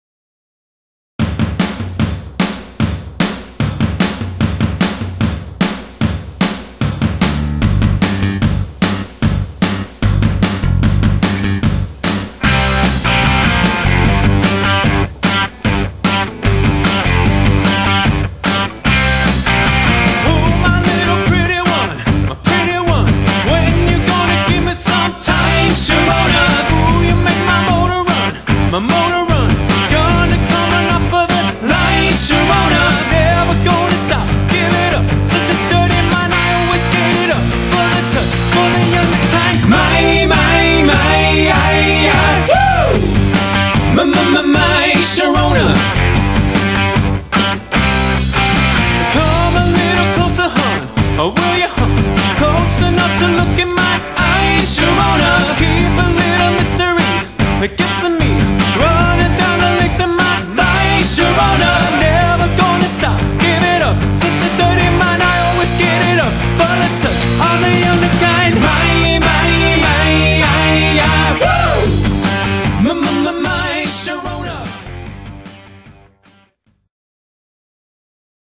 Guitars / Vocals
Bass / Vocal
Drums